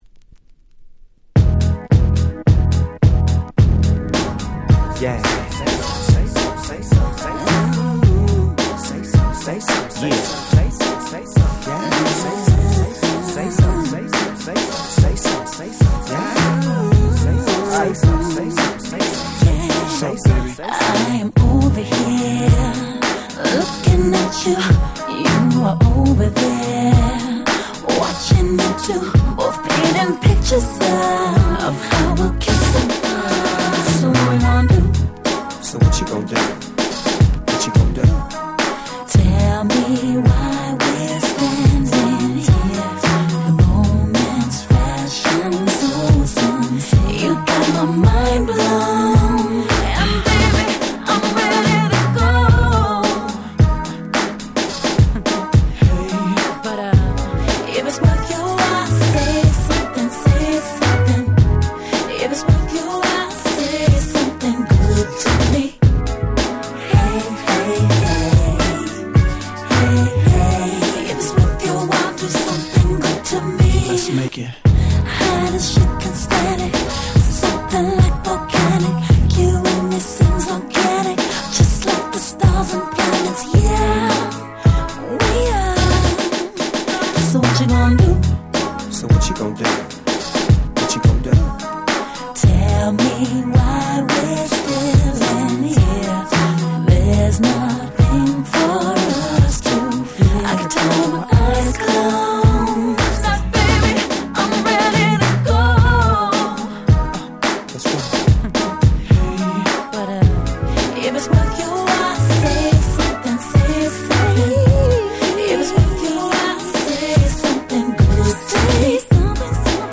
05' Smash Hit R&B !!